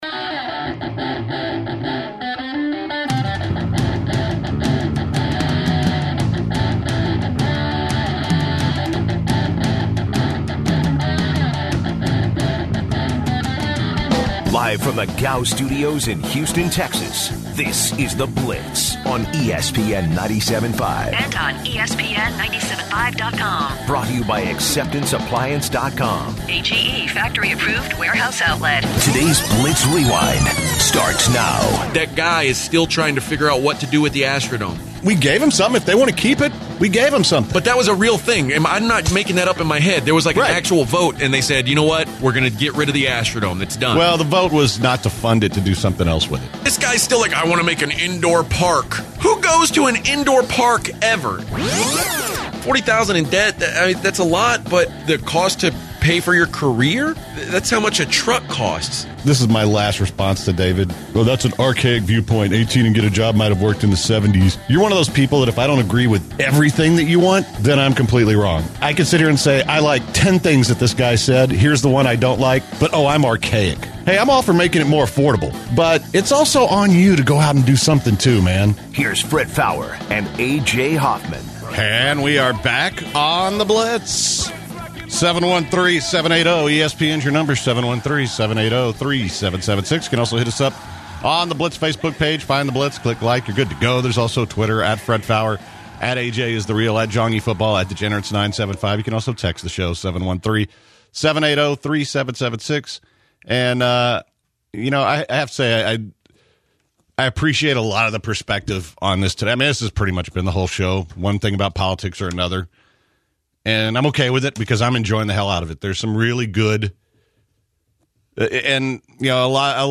The guys continue to speak with callers about secondary education and college education.